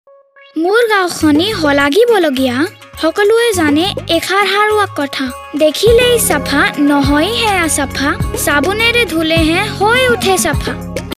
Radio spot Hindi TSC children handwashing soap food eating